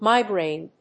音節mi・graine 発音記号・読み方
/mάɪgreɪn(米国英語), míːgreɪn(英国英語)/